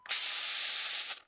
Added new spray sound effects and updated/changed those that were used before for fire extinguisher, refueling/refilling, using cleaner and using weed-b-gone.
refill.ogg